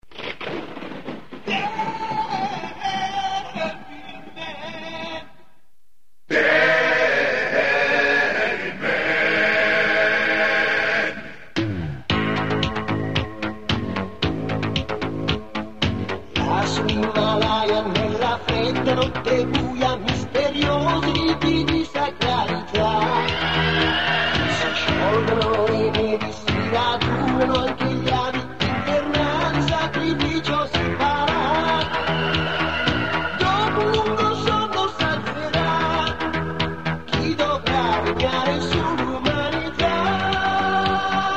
Qualità CD